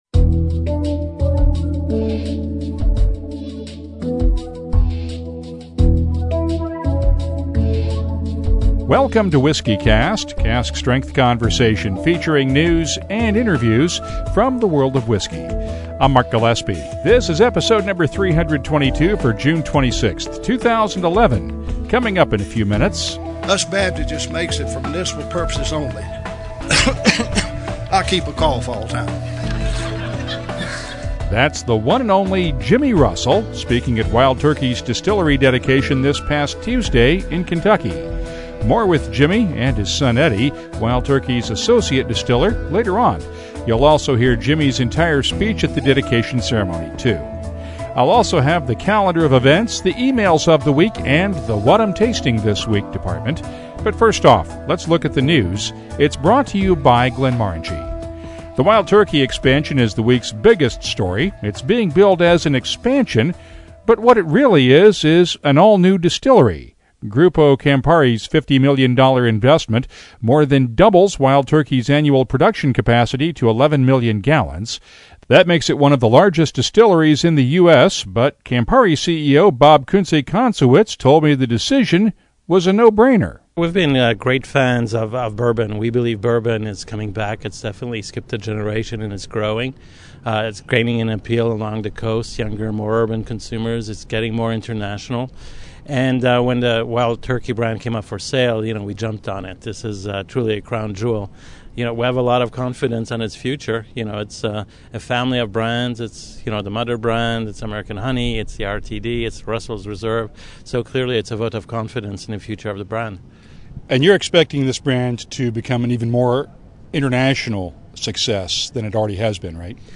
entire speech during the ceremony.